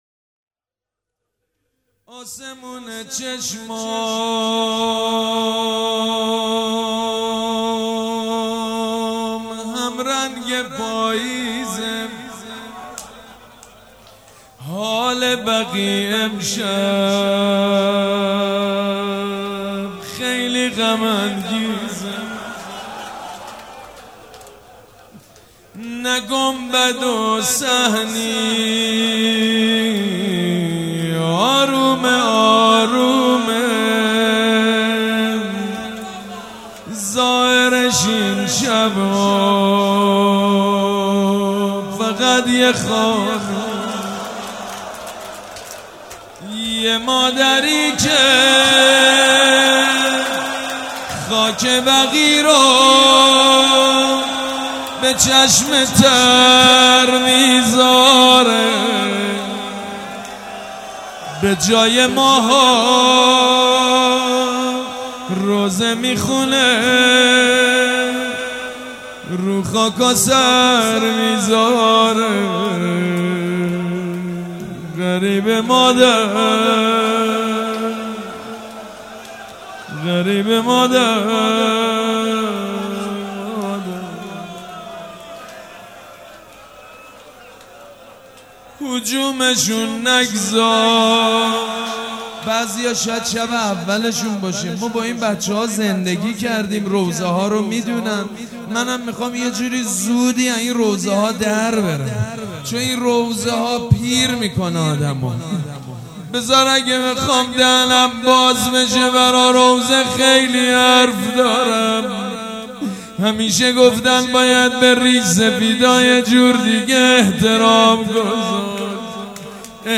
شب شهادت امام صادق(ع) ۹۷
روضه